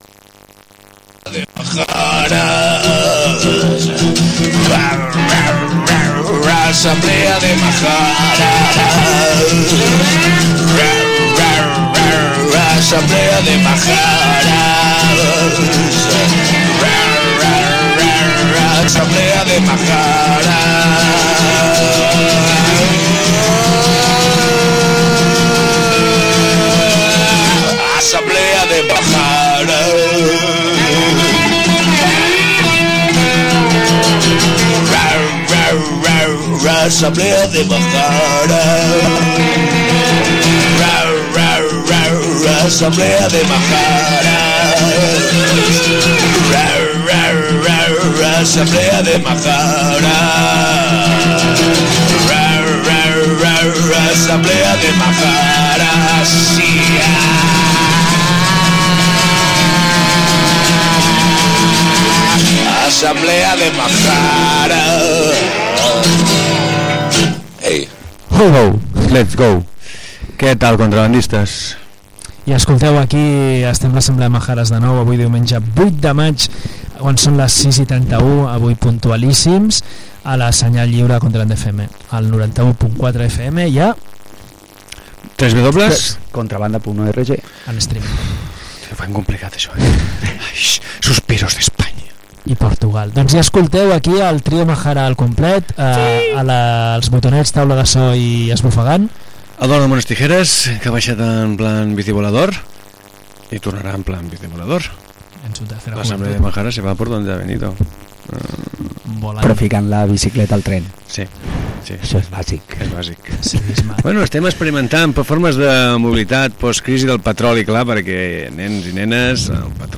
Programa de cròniques judicials.